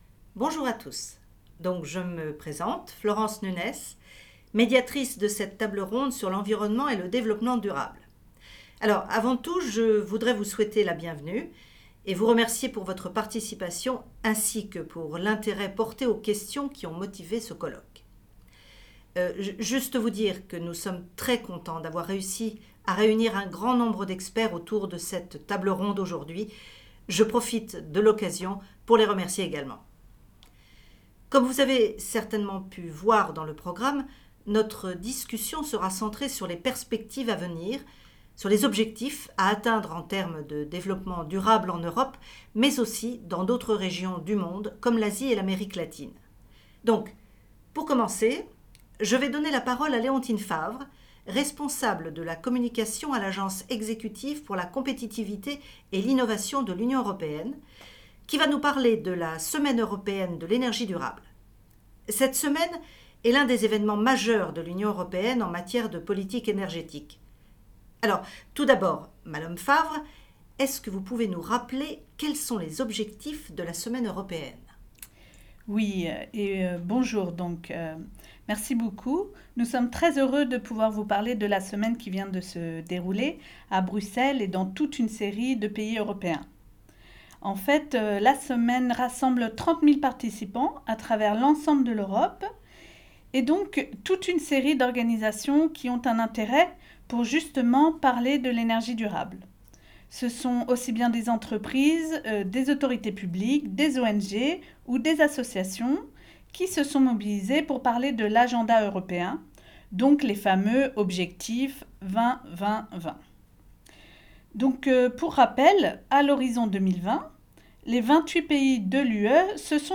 En écoutant ces interventions lors d’événements en Europe et au Sénégal sur le développement durable, vos apprenant(e)s pourront acquérir les compétences pour rédiger un compte-rendu.
Savoir-faire langagier(s) : Identifier les idées principales et secondaires développées dans une discussion de type table-ronde.